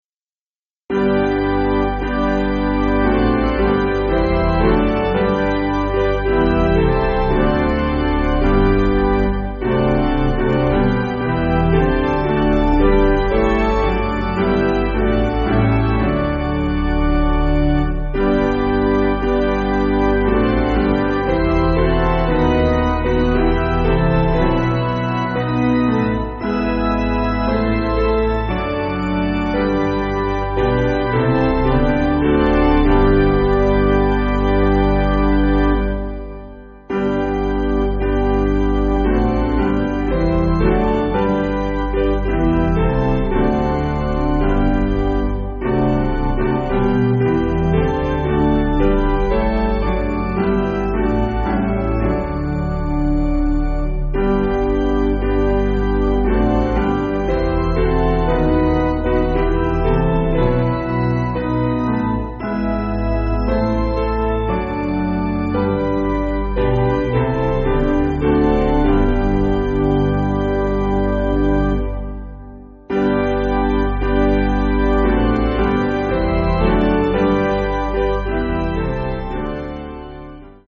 Basic Piano & Organ
(CM)   4/G